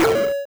treasure-open.wav